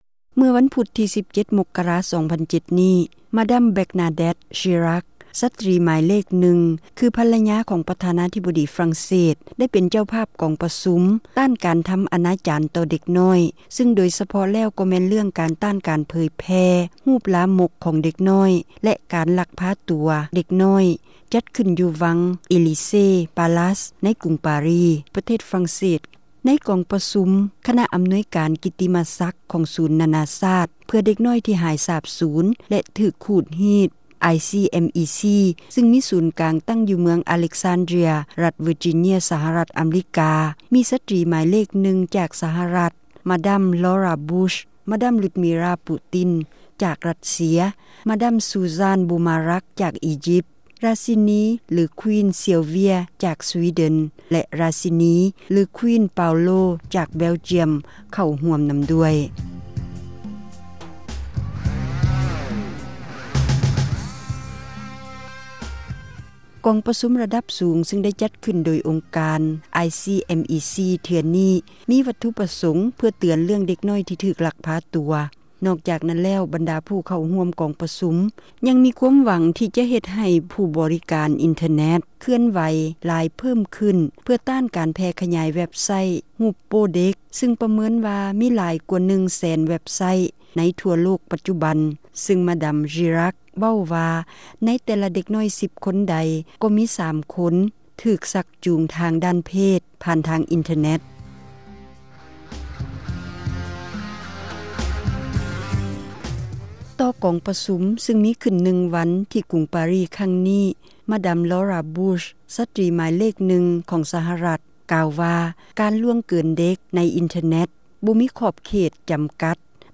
ກອງປະຊຸມ ຕ້ານການທໍາອະນາຈານ ຕໍ່ເດັກນ້ອຍ – ຂ່າວລາວ ວິທຍຸເອເຊັຽເສຣີ ພາສາລາວ